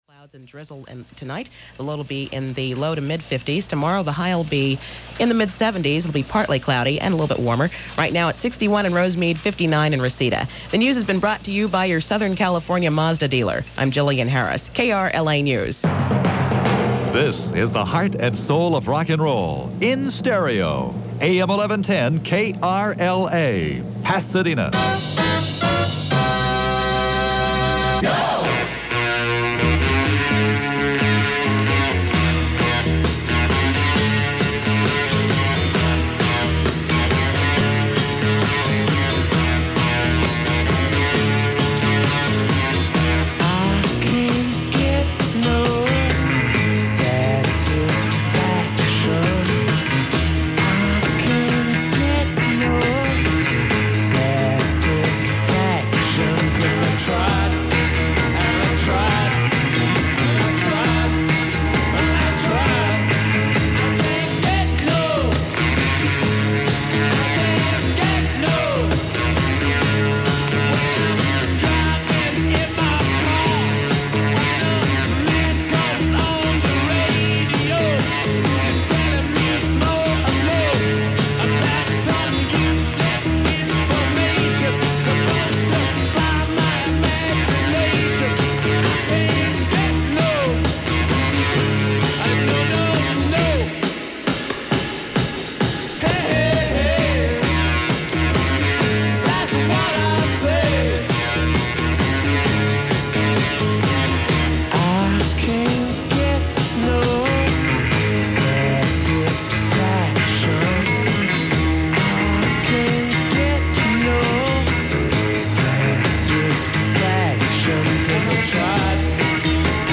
The link above is a one-hour air check taped in March of 1984 using a Sony SRF-A100 AM stereo radio at my home in La Canada.
I chose this one because of the dramatic stereo trip provided by the first three songs.
Oldies often were dubbed from mono 45-rpm records because that was what was available.
I can tell you it measured 30 db when we proofed it.
It was made 2-track stereo 7-1/2 ips from a Sony SRF-A100 radio which contained a true Kahn stereo decoder.